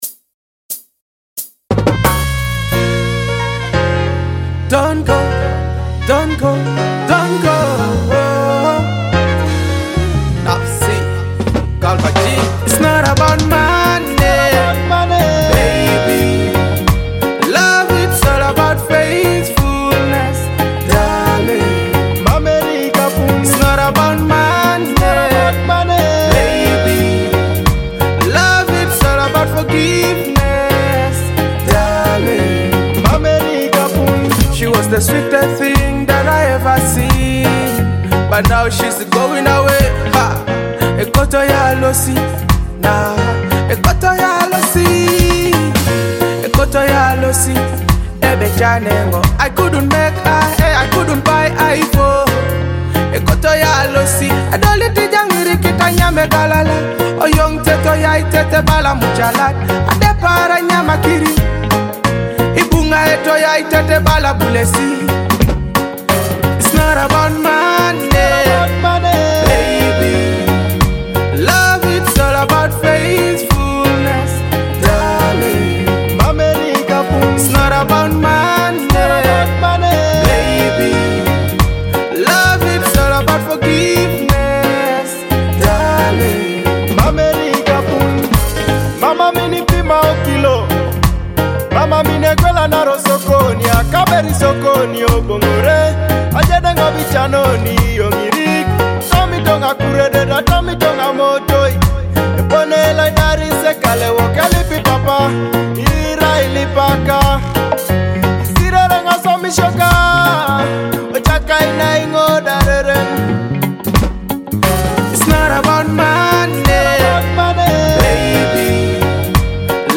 Download and enjoy this feel-good anthem now!